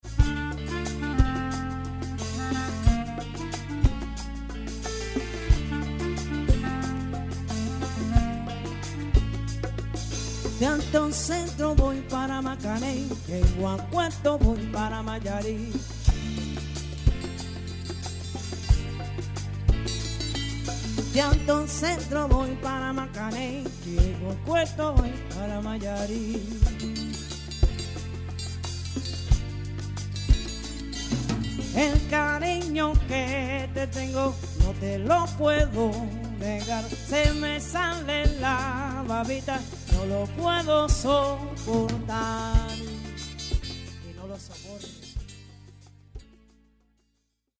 einer Mischung aus Latin, Soul, Reggae und Jazz